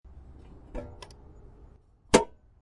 car-gas-cap-opening-automatic-98900.mp3